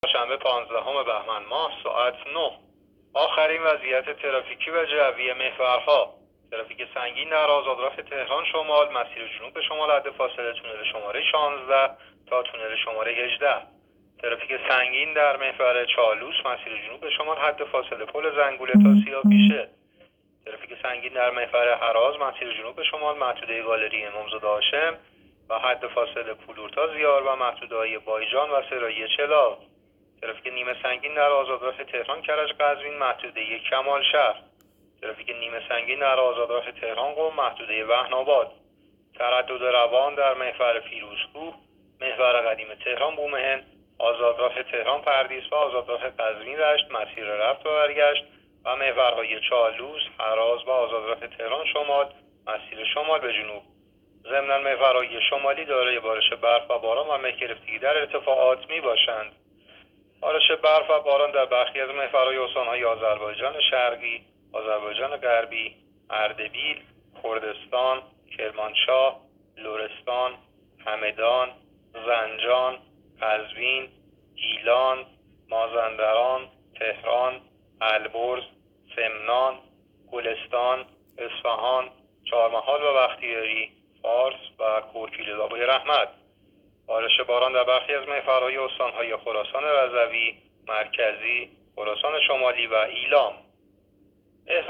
گزارش رادیو اینترنتی از آخرین وضعیت ترافیکی جاده‌ها ساعت ۹ پانزدهم بهمن؛